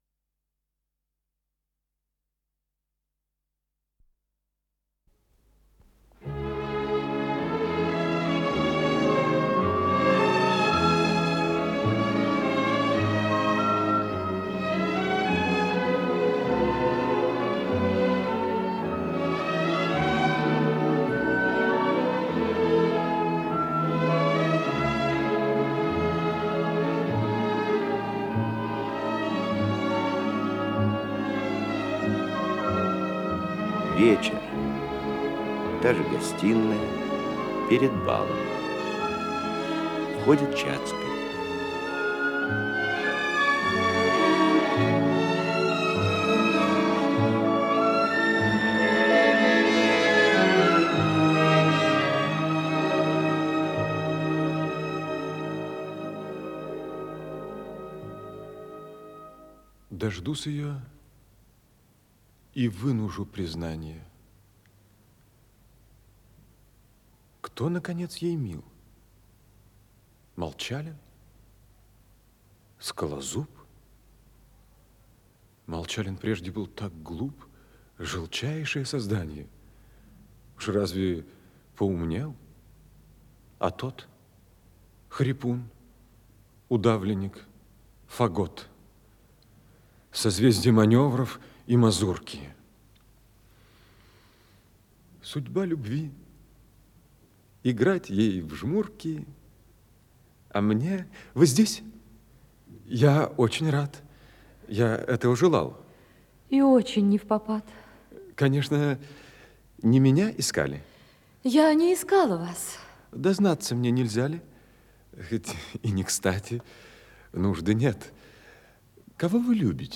Исполнитель: Артисты Малого театра
Спектакль Государственного академического Малого театра СССР